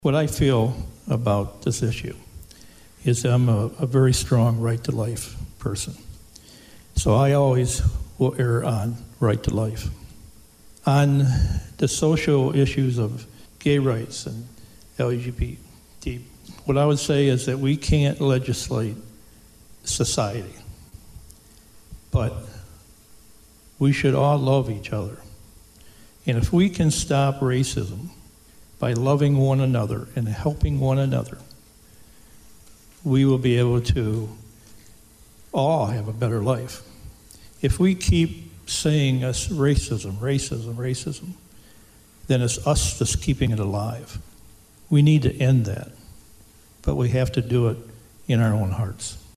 Adrian, MI – With the reversal of Roe v. Wade last week, the topic of abortion will now go to the states to decide…and a few local candidates for State House were asked about reproductive rights at a recent forum.